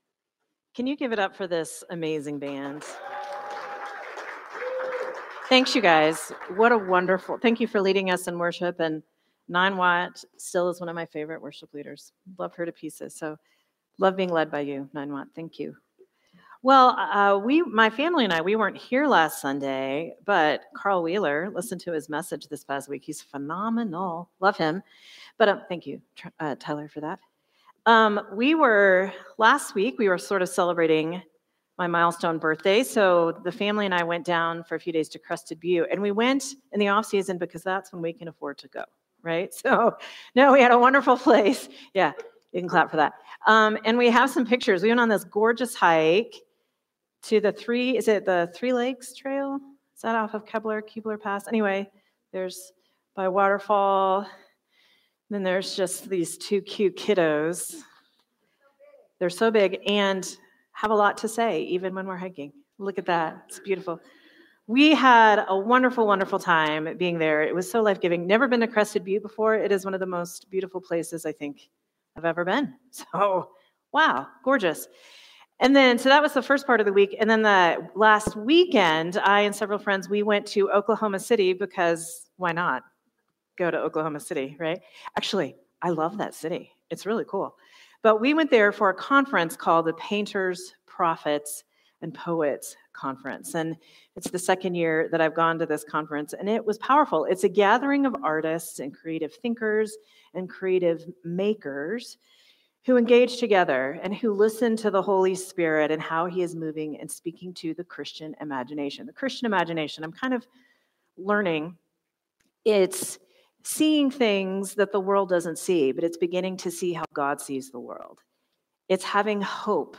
Sermon from Celebration Community Church on October 26, 2025